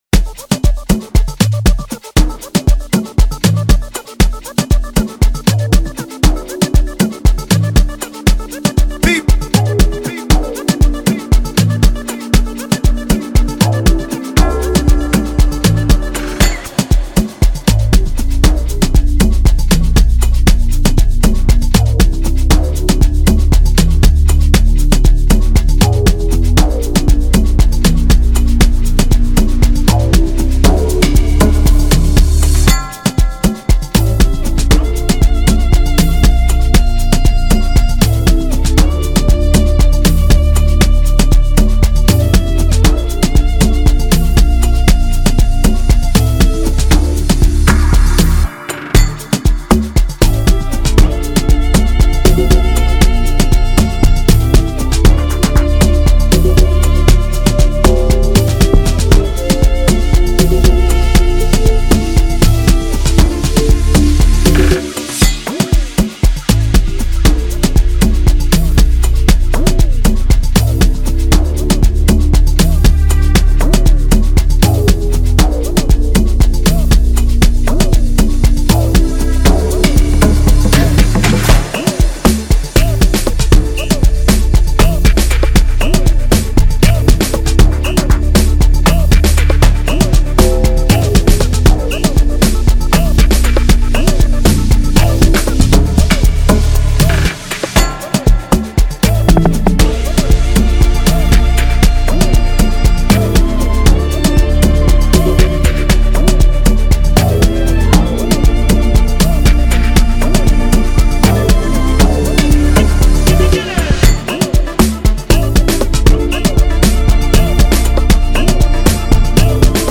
2024 in Dancehall/Afrobeats Instrumentals